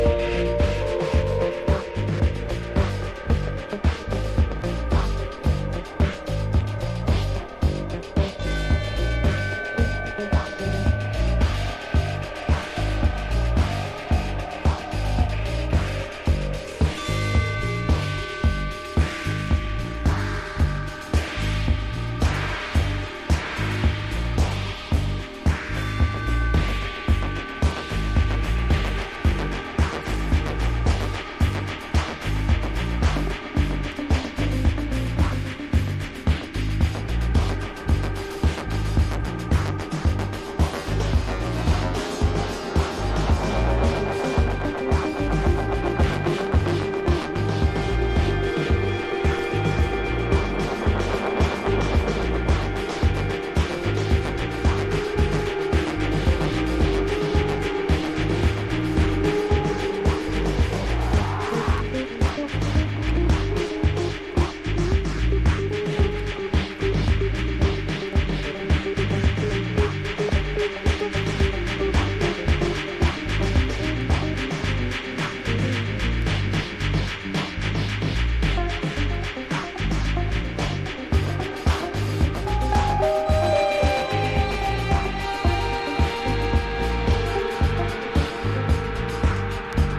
1. HOUSE >
楽曲はサイケデリックなビート・ダウンを展開。